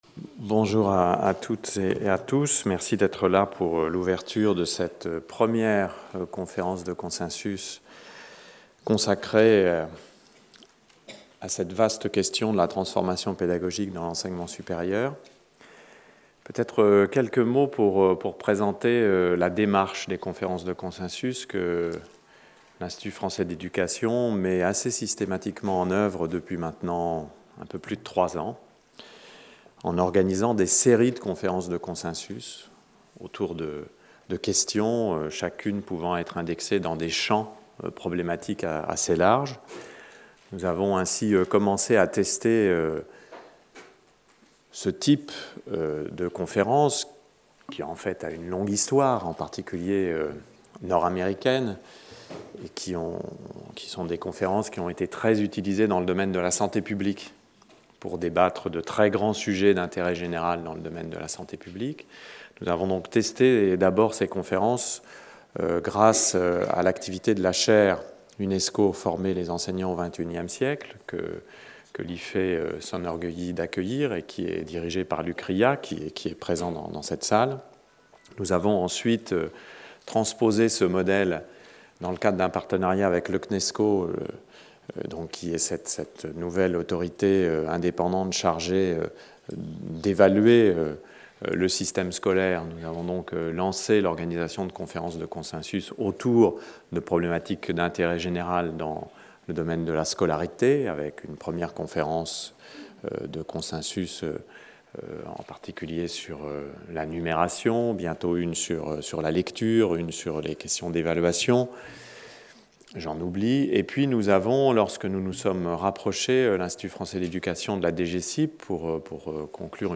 Discours d'ouverture de la première conférence de consensus sur la transformation pédagogique de l'enseignement supérieur: Réussite et échec dans l'enseignement supérieur: quels éclairages de la recherche? Discours de Michel Lussault, directeur de l'Institut français de l'Éducation (IFÉ) de l'ENS de Lyon.